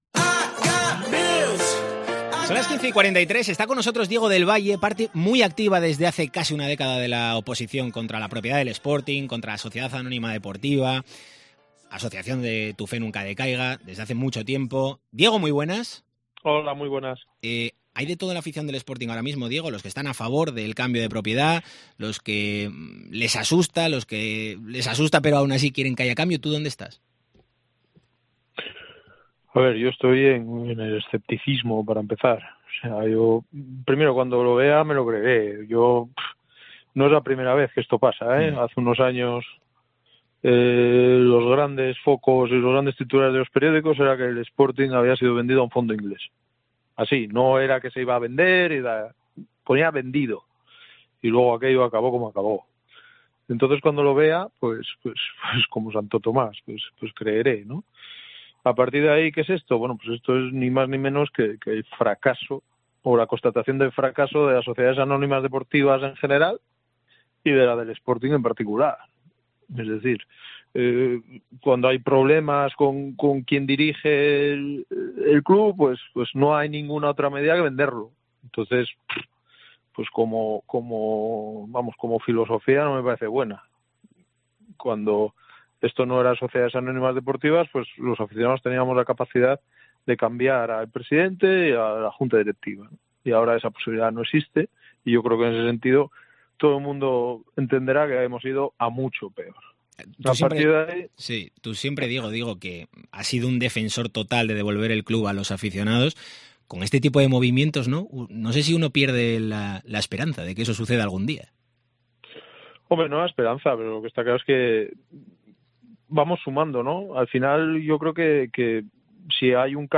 Hemos hablado con él en Deportes COPE Asturias para conocer su opinión sobre Orlegi Sports, el grupo interesado en comprar el Sporting.